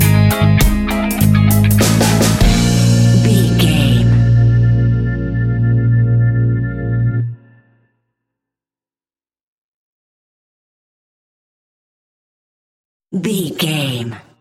Aeolian/Minor
B♭
laid back
chilled
off beat
drums
skank guitar
hammond organ
percussion
horns